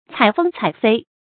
采葑采菲 注音： ㄘㄞˇ ㄈㄥ ㄘㄞˇ ㄈㄟ 讀音讀法： 意思解釋： 葑：蔓青，葉和根、莖都可食，但味苦；菲：蕪菁類植物。